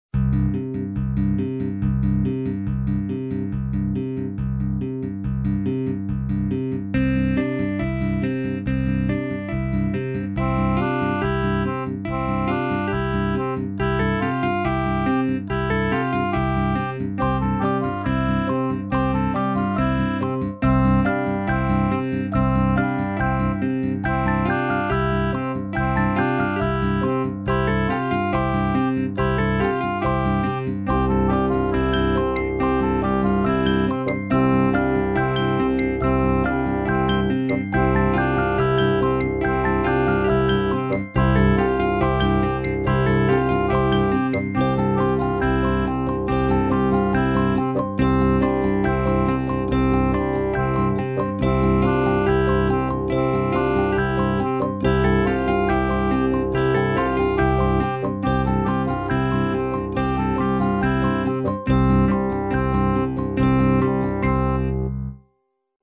PB Frere Jacques en do.mp3